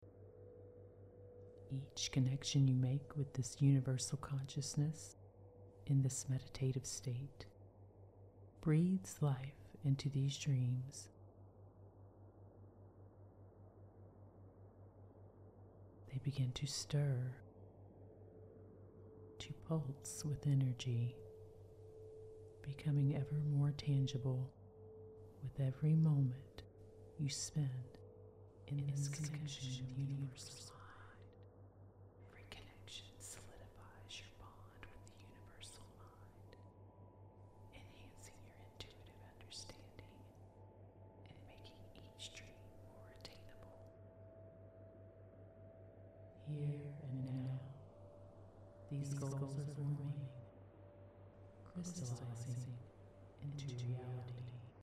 • Theta Binaural Track: Integrated mid-session for this extended version, these theta waves deepen relaxation and meditation, making it easier to connect with the universal mind.
Use headphones to fully experience the intricate soundscapes and binaural beats. This is a theta track – do not drive or operate heavy machinery while using this MP3.
Universal-Mind-Hypnosis-Sample.mp3